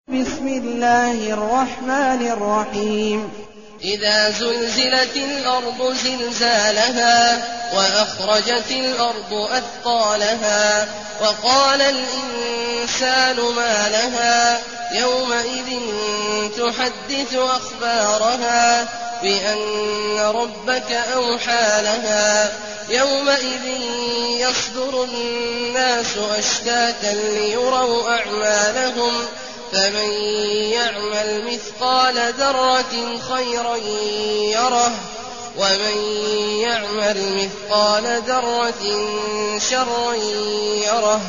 المكان: المسجد النبوي الشيخ: فضيلة الشيخ عبدالله الجهني فضيلة الشيخ عبدالله الجهني الزلزلة